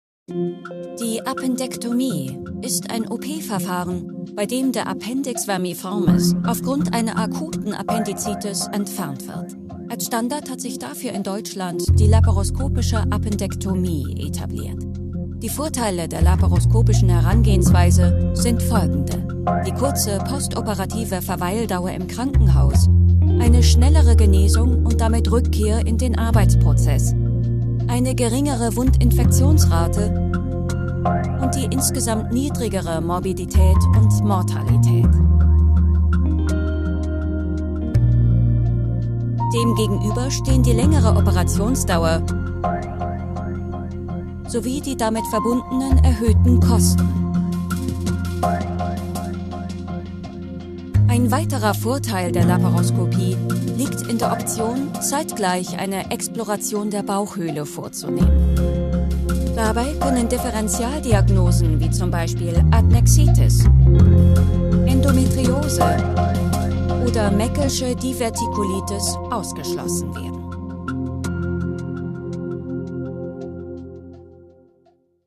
deutsche Profi Sprecherin.
Sprechprobe: eLearning (Muttersprache):